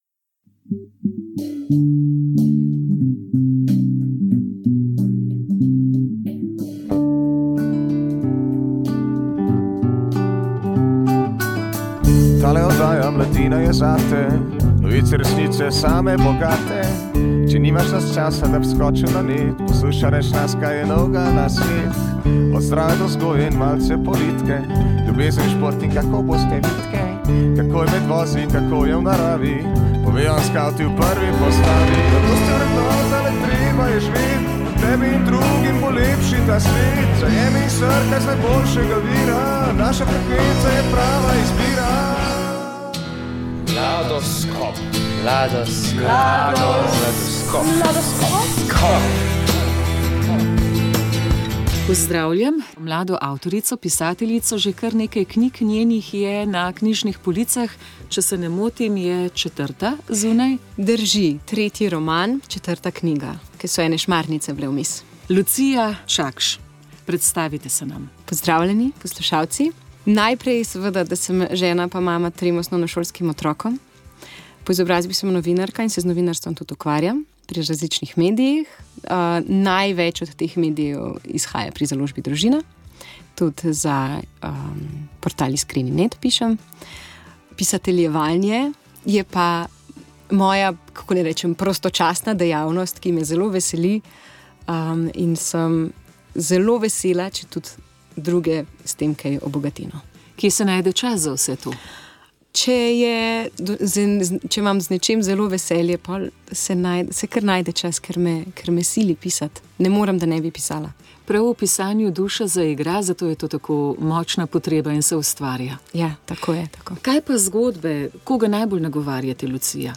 Zgodbe za otroke mladi otroci vzgoja šmarnice pravljice